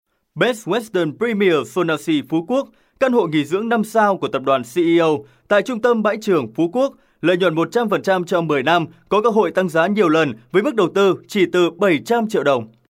Voice overs produced by US and international actors.